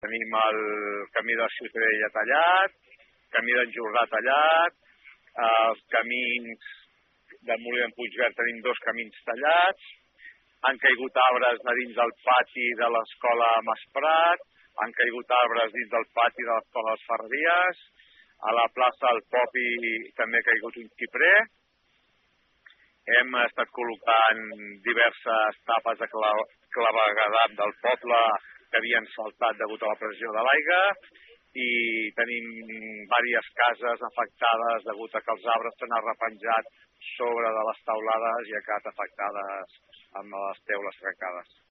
En parlava aquest matí, en declaracions a aquesta emissora